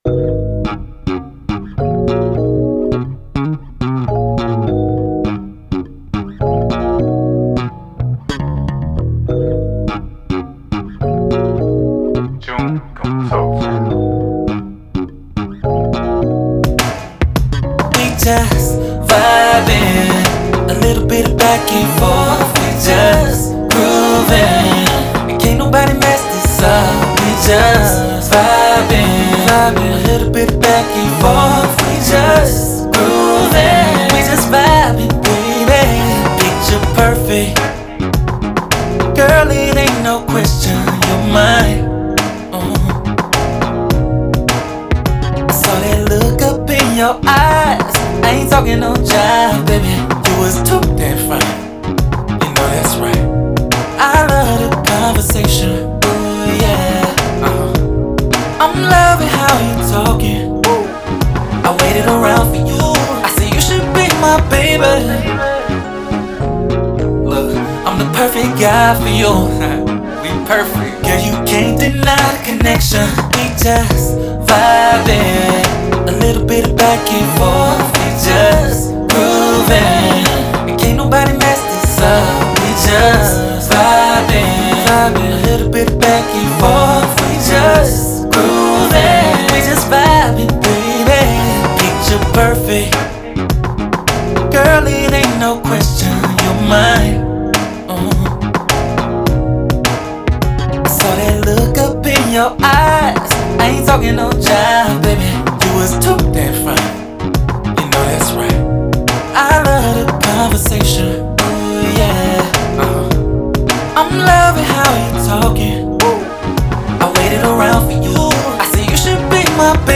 R&B
Eb Min